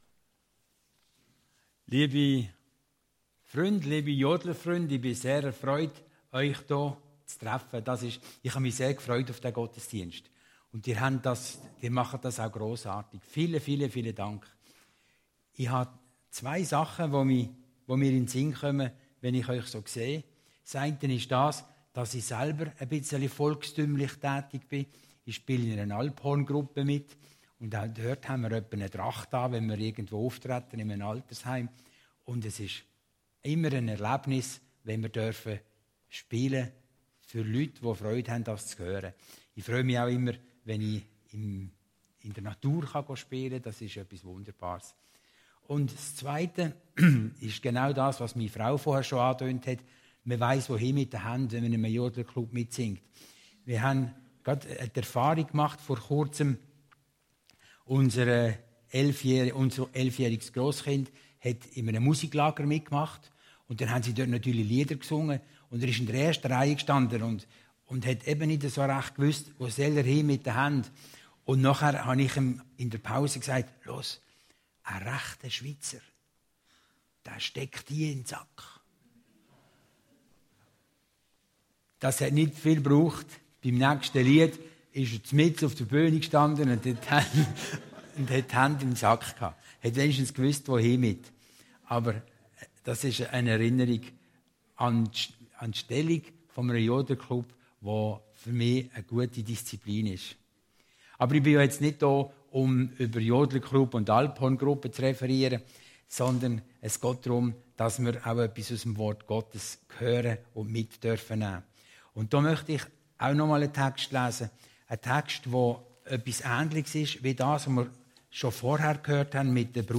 Predigten Heilsarmee Aargau Süd – Baum am Wasser